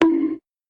Coconutbonk.mp3